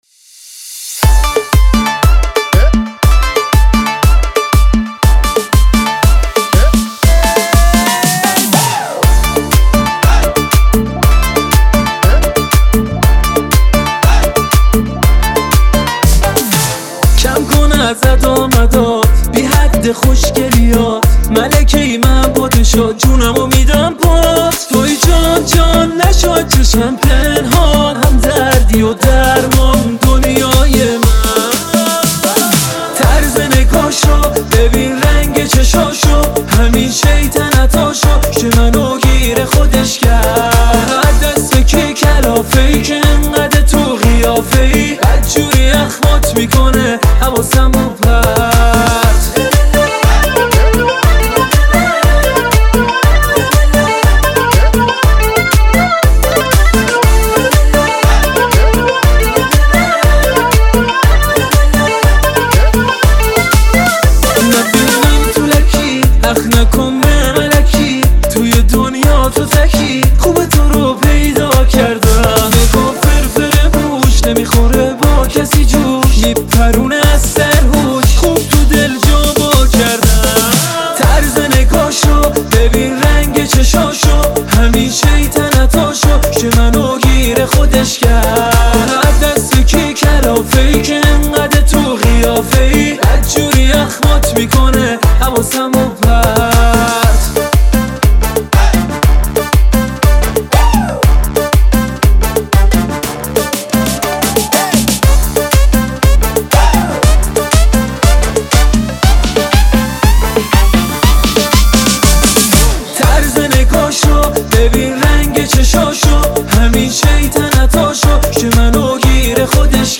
آهنگ خیلی شاد قشنگ